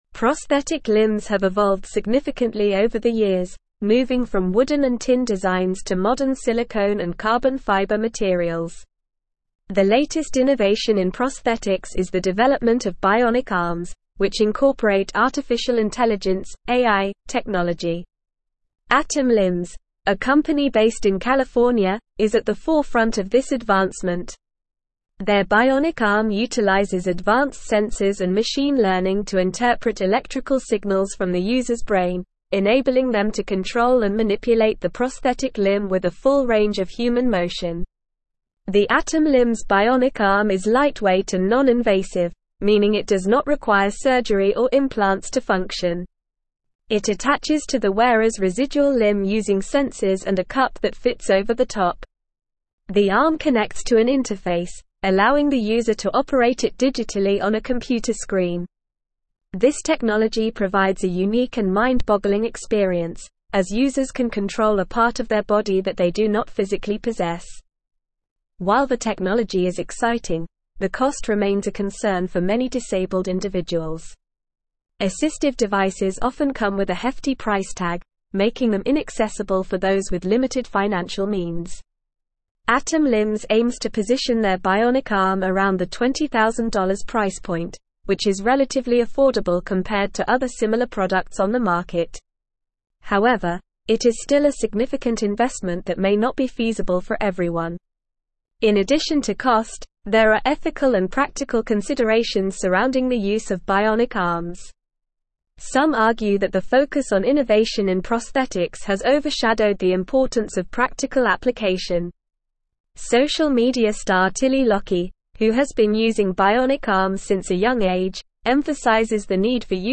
Normal
English-Newsroom-Advanced-NORMAL-Reading-Next-Gen-Bionic-Arm-AI-Sensors-and-Affordable-Innovation.mp3